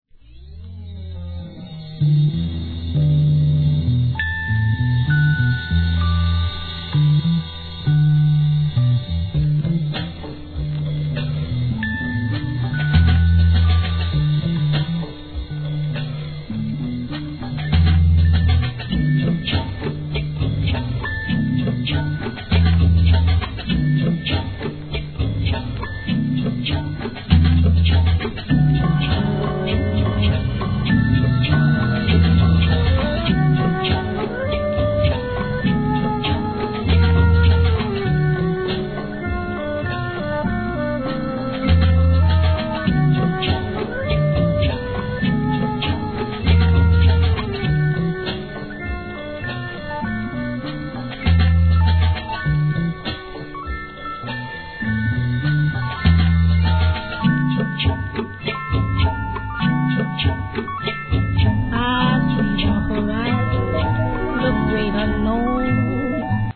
HIP HOP/R&B
まさに今までになかった無国籍サウンドで楽しませまっす!!